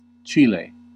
Ääntäminen
Vaihtoehtoiset kirjoitusmuodot (vanhahtava) Chili Tiele Ääntäminen US Tuntematon aksentti: IPA : /ˈtʃɪl.i/ US : IPA : /ˈtʃiː.leɪ/ Haettu sana löytyi näillä lähdekielillä: englanti Käännös Erisnimet 1. Chile {das} Määritelmät Erisnimet A country in South America .